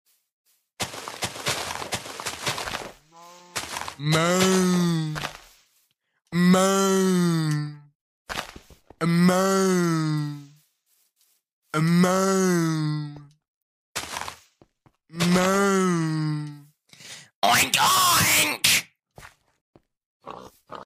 Animal Sounds MOO MOO OINK OINK